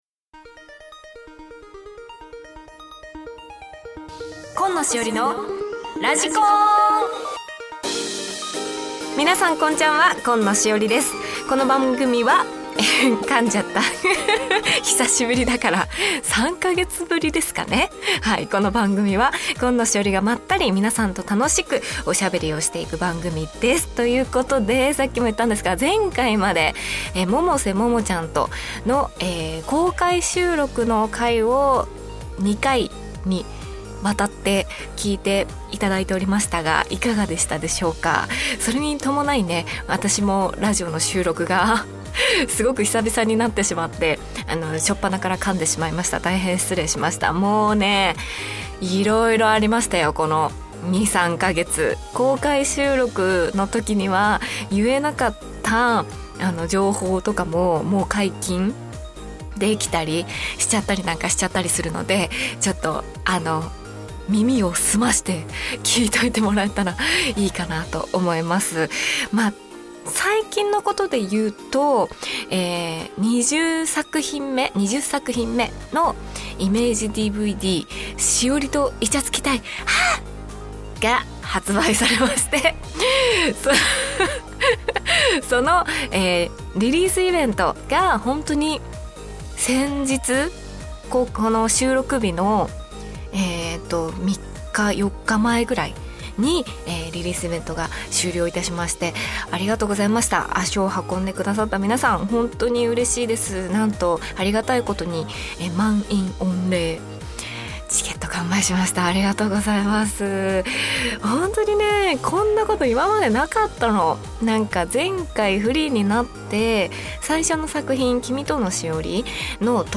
久しぶりのスタジオ収録でいつもよりちょっぴりカミカミになっちゃいました！？